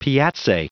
Prononciation du mot piazze en anglais (fichier audio)
Prononciation du mot : piazze